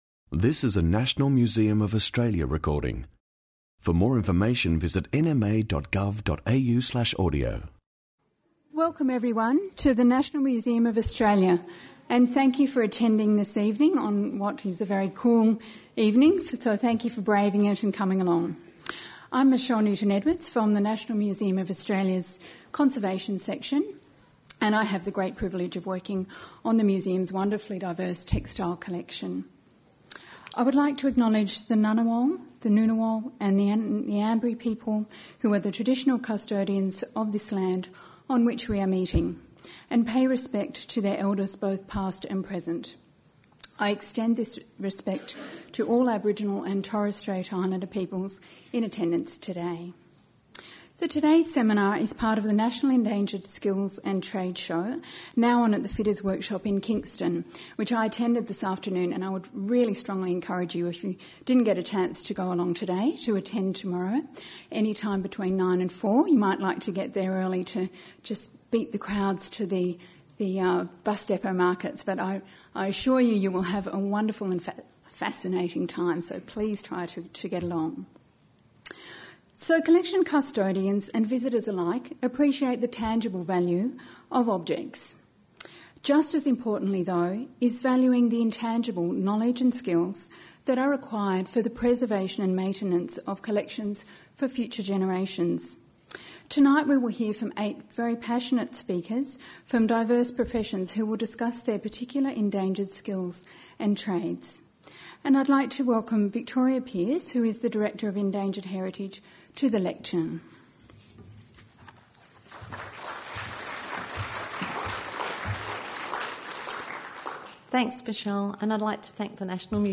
At the Endangered Skills and Trades seminar, artisans share their stories of the joys and challenges of preserving rare skills and trades. Learn how bookbinders, taxidermists and even locksmiths can provide skills and solutions for an innovative future.